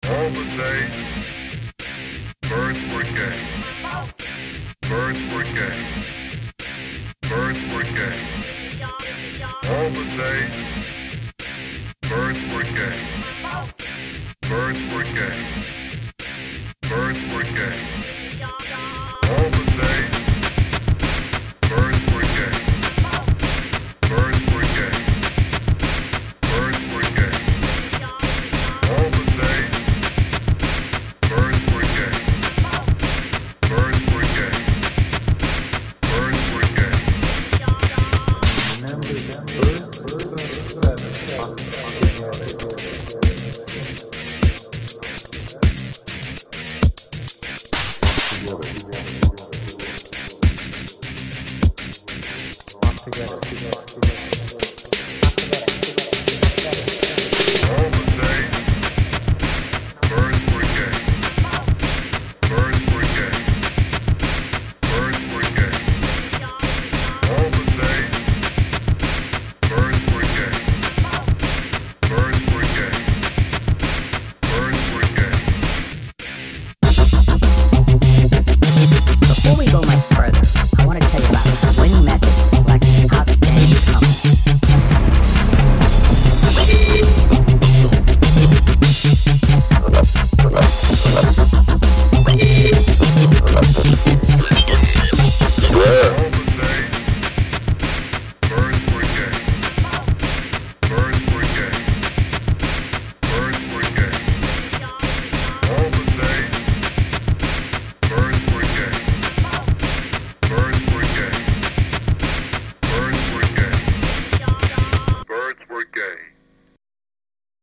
The wubs on the bridge are amazing!